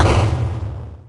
clear_2.ogg